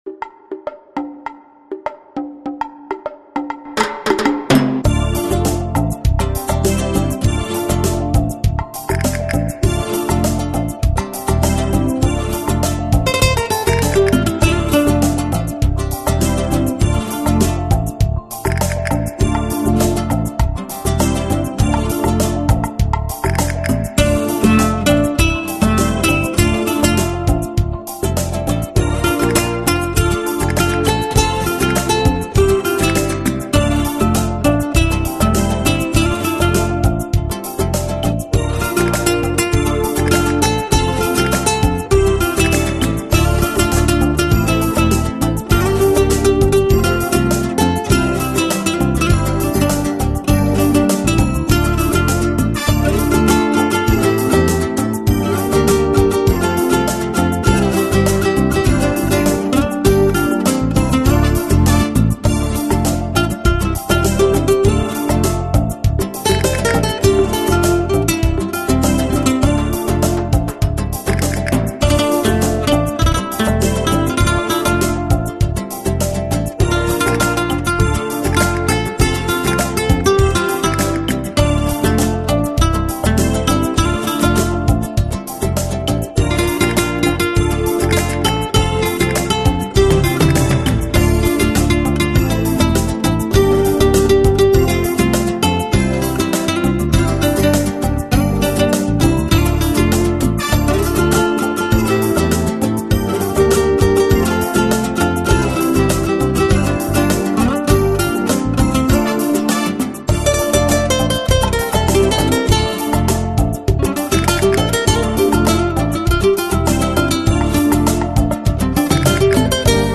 【所属类别】音乐 新世纪音乐
西班牙吉他大师倾情演奏，全新数码混制，音色更靓，
质感更好，吉它技艺无懈可击，百听不厌。
这张西班牙吉它录音实在太精彩，把西班牙吉它应有的优美热情和动感一一